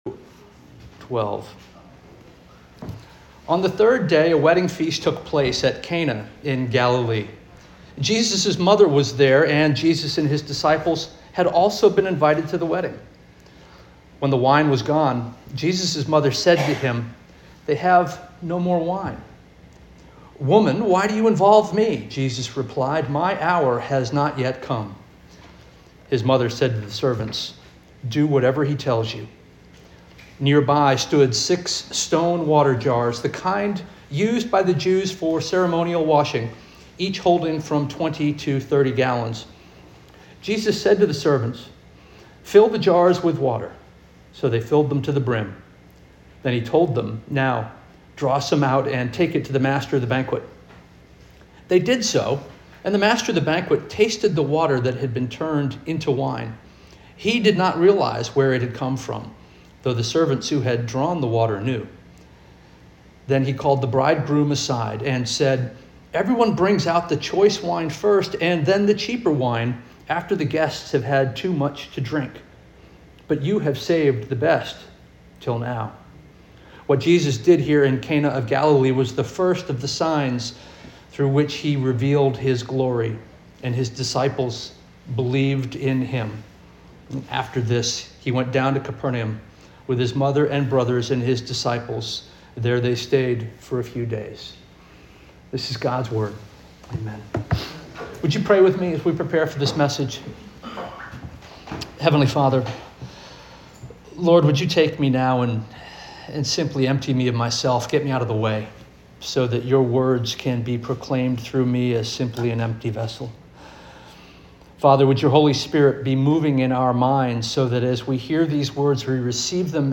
August 24 2025 Sermon